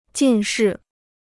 近视 (jìn shì) Free Chinese Dictionary